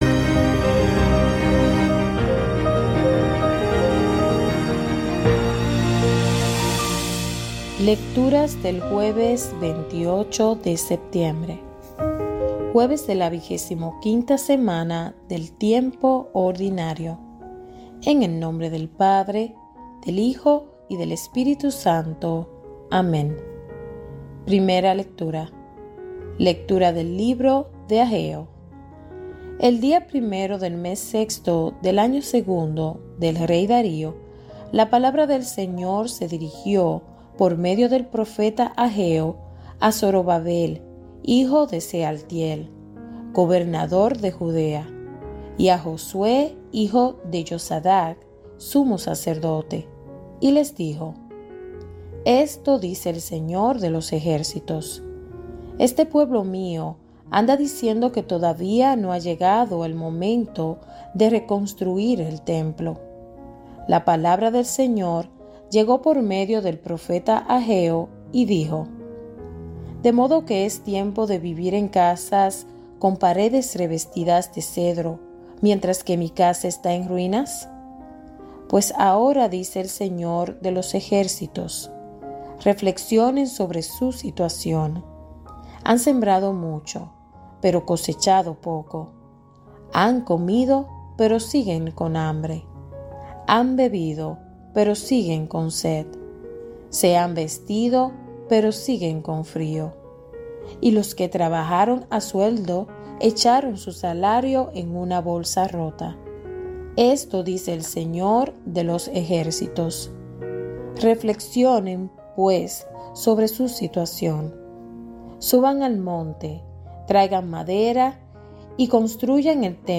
Lecturas del día
Lecturas-del-dia-sabado-28-de-septiembre.mp3